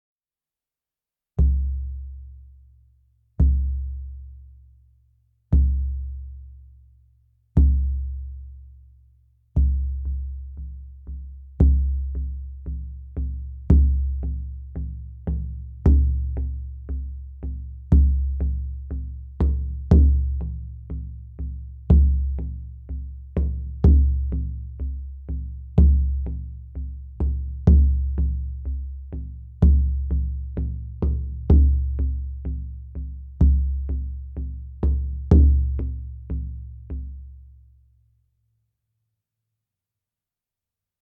Meinl Sonic Energy Bodhran Drum 18" - Napa Head (BD18WB-NH)